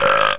litlburp.mp3